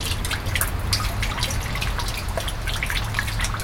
Sink Faucet | Sneak On The Lot